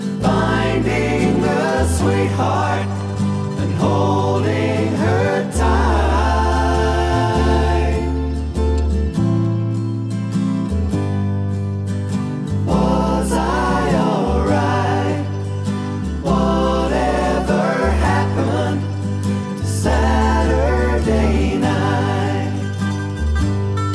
Minus Mandolins Soft Rock 3:21 Buy £1.50